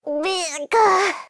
Cri de Pikachu K.O. dans Pokémon X et Y.